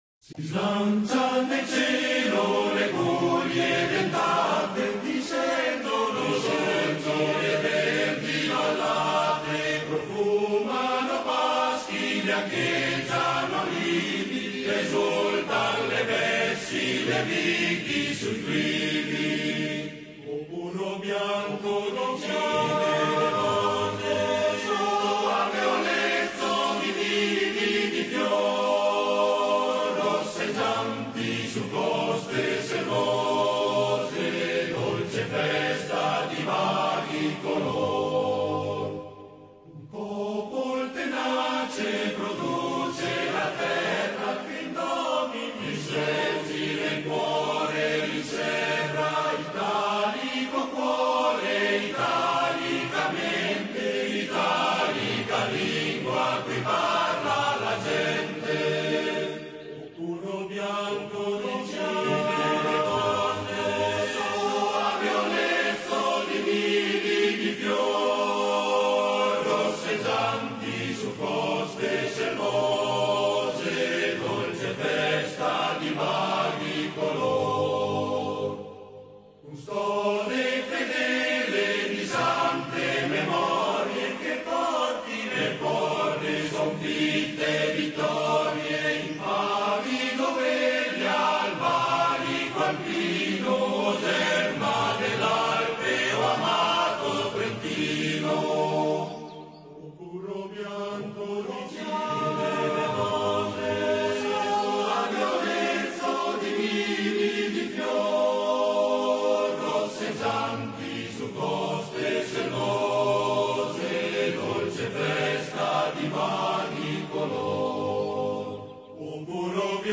InnoalTrentino-Coro-SOSAT.mp3